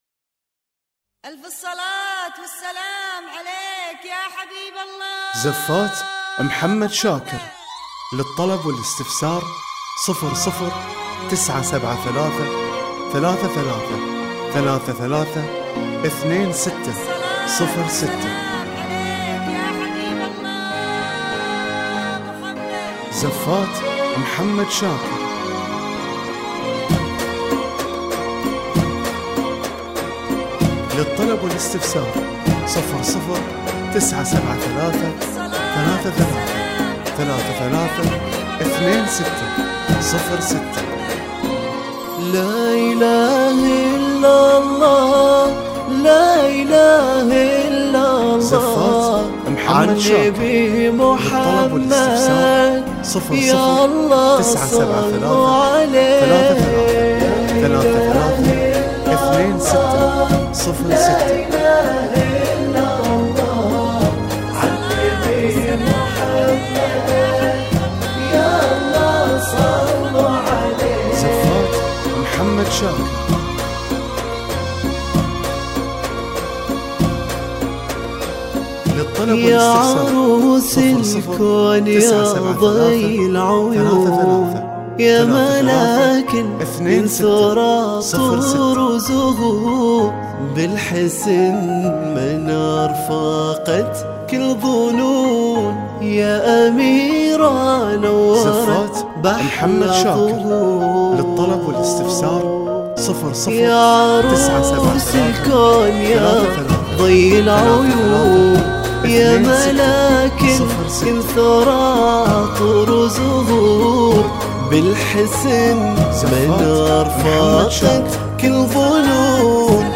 وتتوفر بالموسيقى وبدون موسيقى